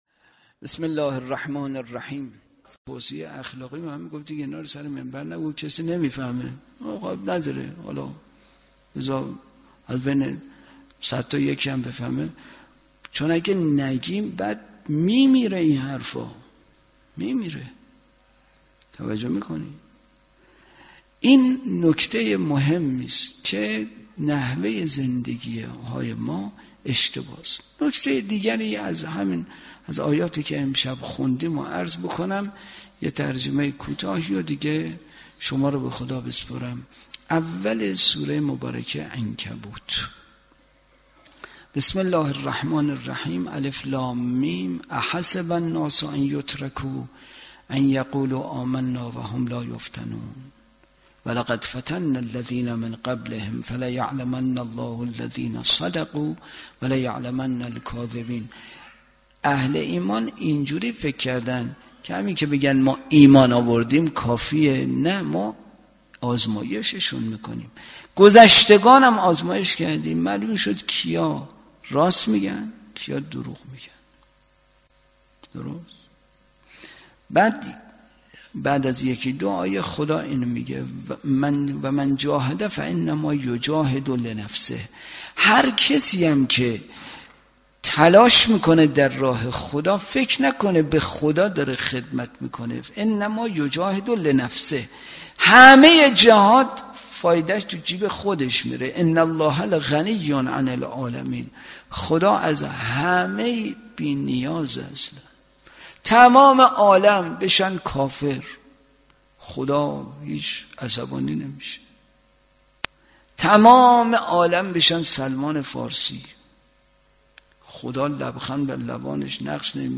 برگزارکننده: مسجد اعظم قلهک